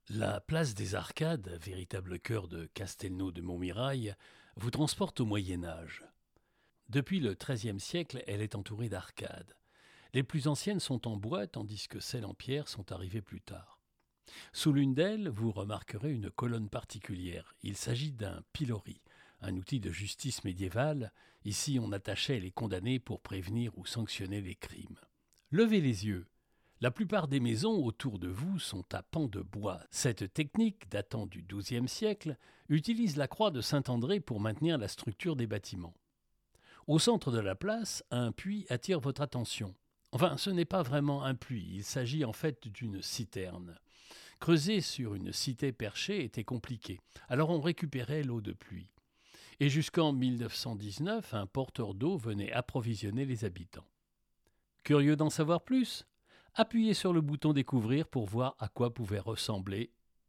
Voix off
50 - 100 ans - Baryton-basse